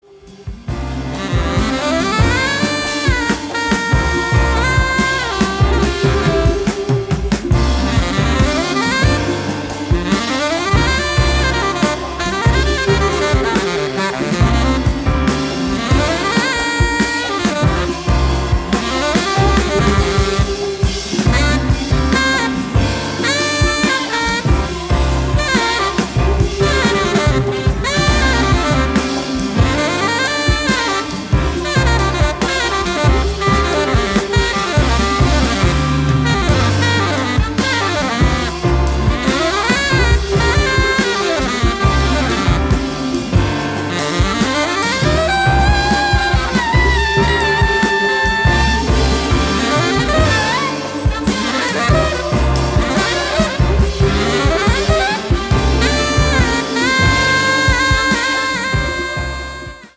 Superb modal/soul-jazz set